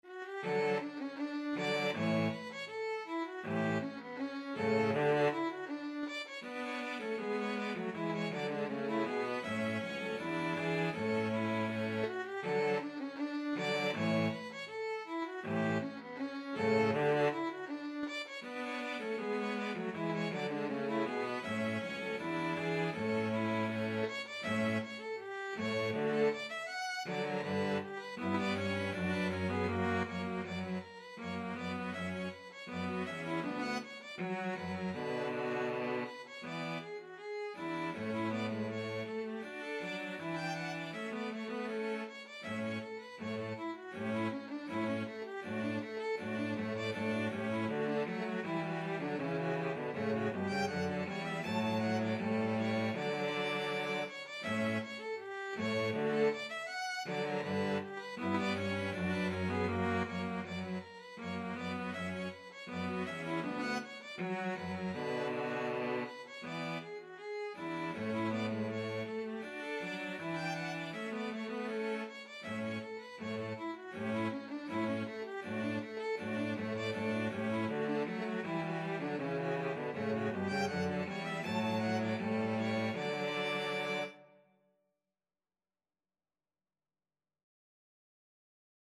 Free Sheet music for String trio
ViolinCello 1Cello 2
D major (Sounding Pitch) (View more D major Music for String trio )
Classical (View more Classical String trio Music)
bach_bourree_STRT.mp3